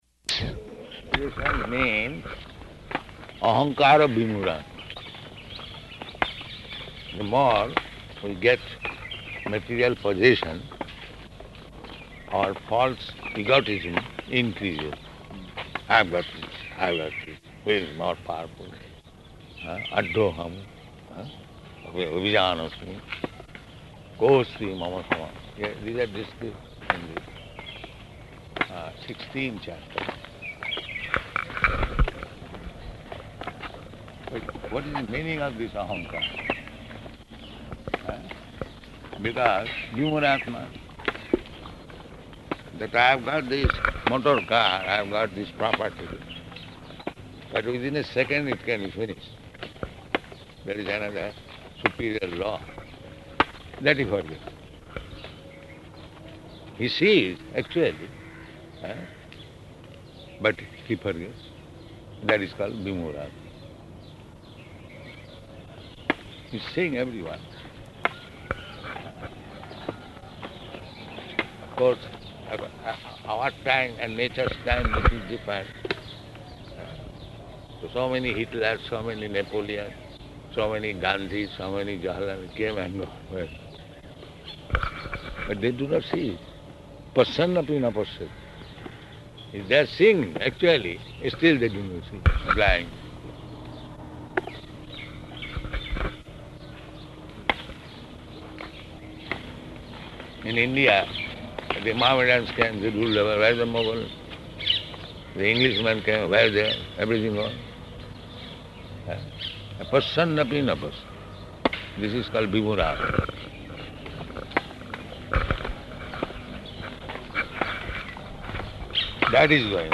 Morning Walk --:-- --:-- Type: Walk Dated: March 25th 1976 Location: Delhi Audio file: 760325MW.DEL.mp3 Prabhupāda: ...means ahaṅkāra-vimūḍhātmā [ Bg. 3.27 ].